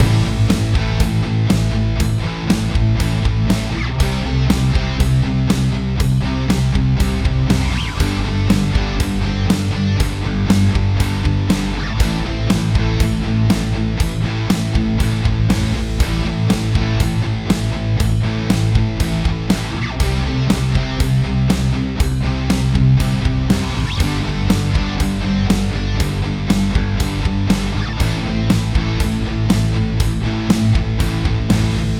Ich häng dir von meinem neuen Song nen kurzen Ausschnitt mit den Modo Drums an. Ist aber nur ne Rohfassung.